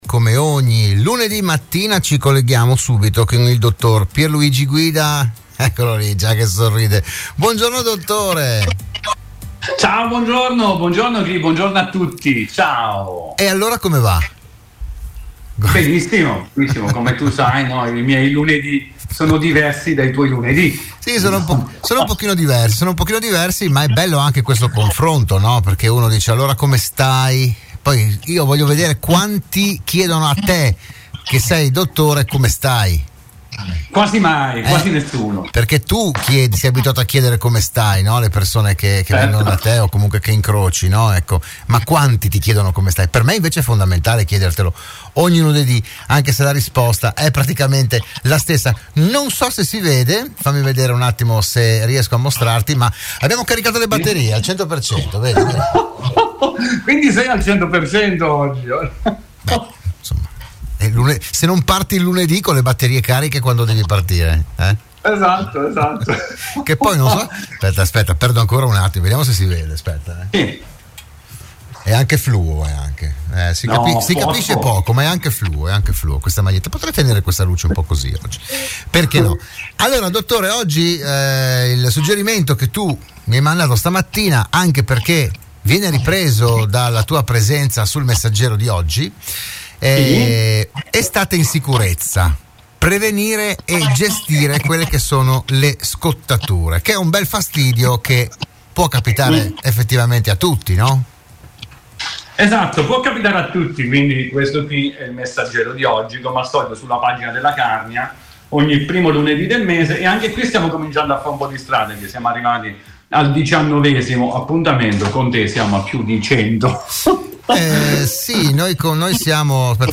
Nuova puntata per “Buongiorno Dottore”, il programma di prevenzione e medicina in onda all’interno della trasmissione di Radio Studio Nord “RadioAttiva”.